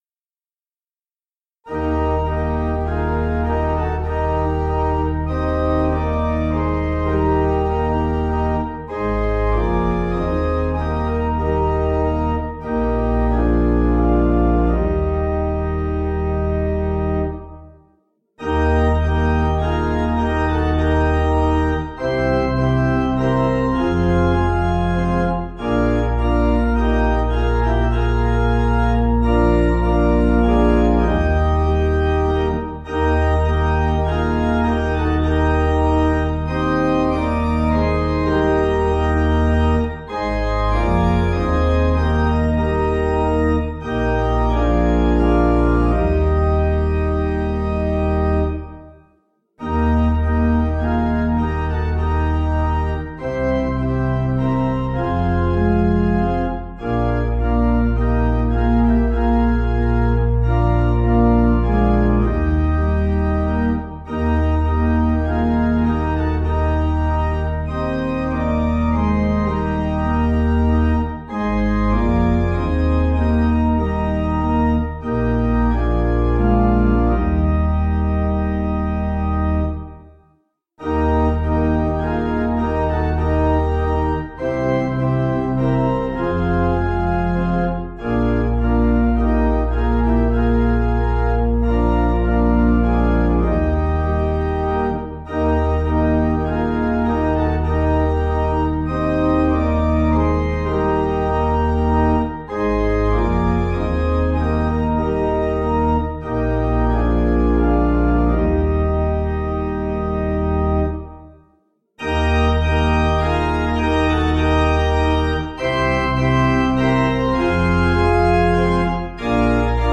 (CM)   4/Eb 484.5kb